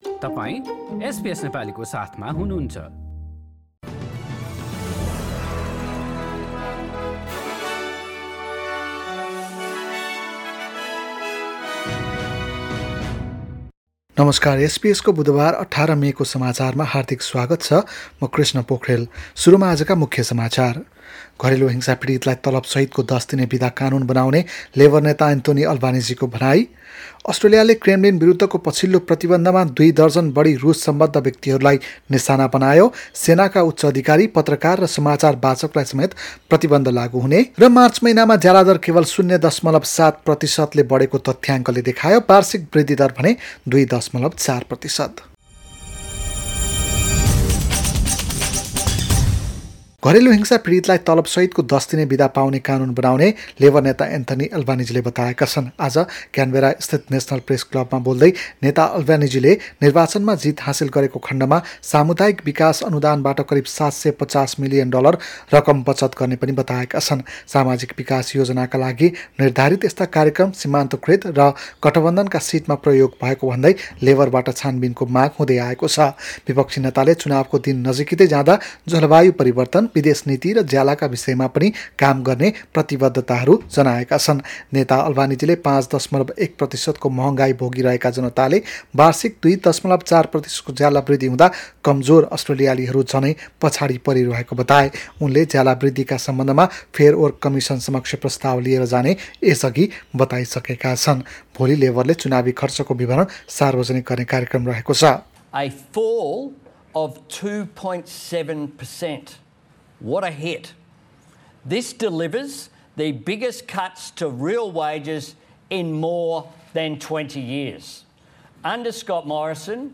एसबीएस नेपाली अस्ट्रेलिया समाचार: बुधबार १८ मे २०२२